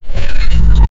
Royalty-free transactions sound effects
purchase-sound-effect-rea-vcqzxdut.wav